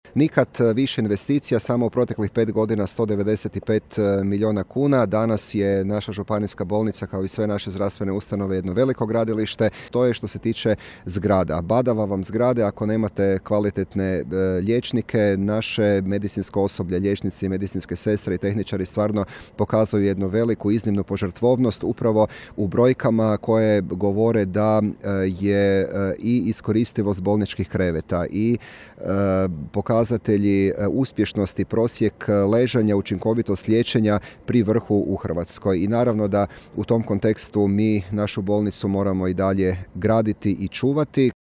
To pokazuje opstojnost i učinkovitost, istaknuo je između ostalog župan Matija Posavec.